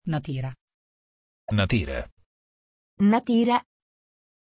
Pronunciation of Natyra
pronunciation-natyra-it.mp3